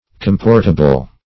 Meaning of comportable. comportable synonyms, pronunciation, spelling and more from Free Dictionary.